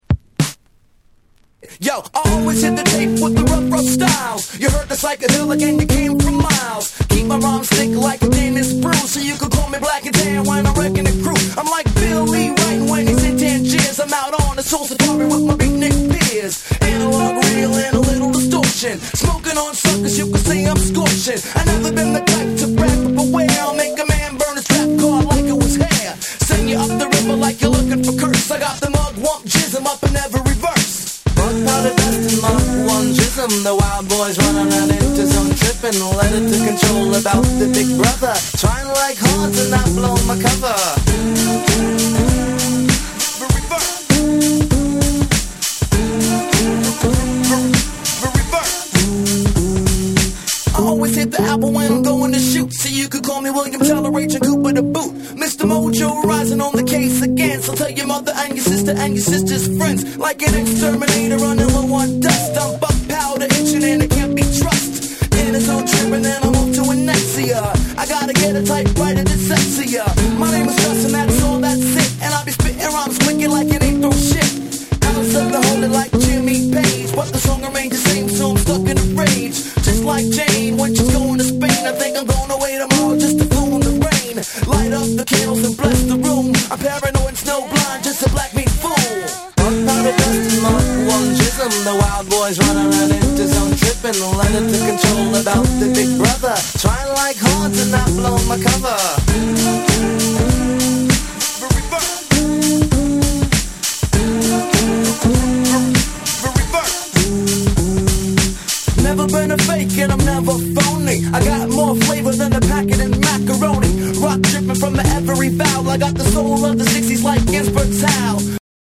BREAKBEATS